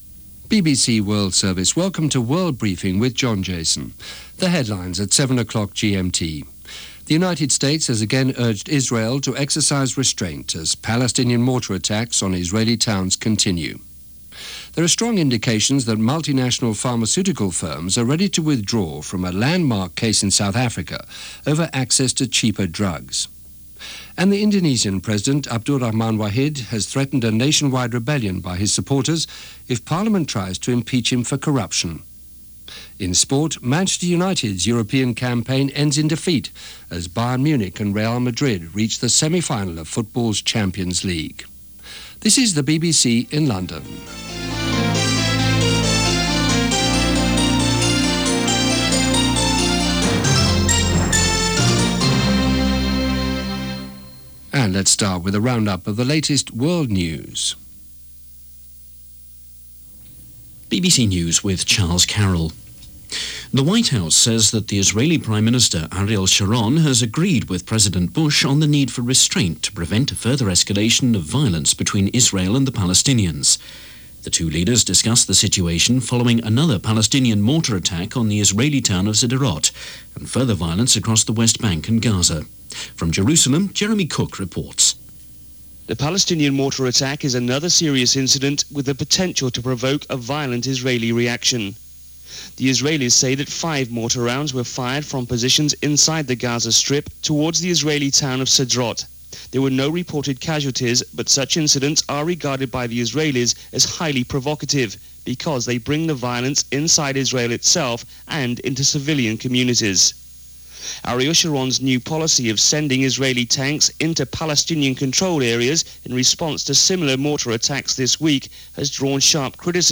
Middle-East: Urging Restraint - Affordable Medicine - Peaceful End Of Coup In Burundi - April 19, 2001 - BBC World Briefing.